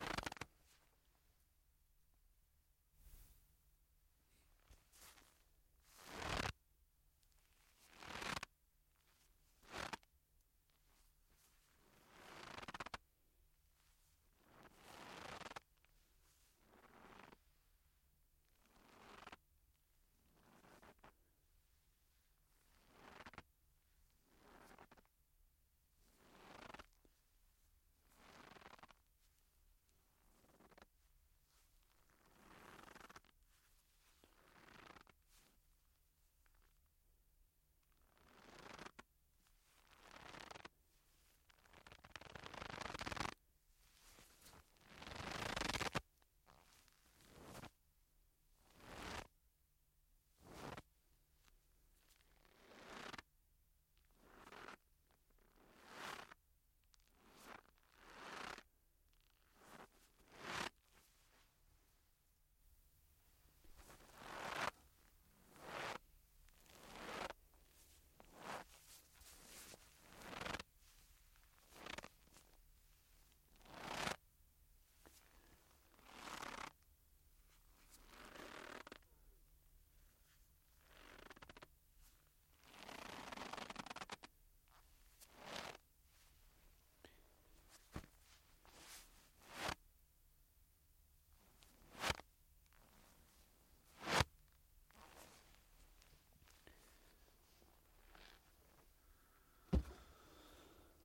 雪地上的脚步声
描述：它是雪上的脚步声。
Tag: 冬天 足迹 行走